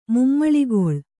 ♪ mummaḷigoḷ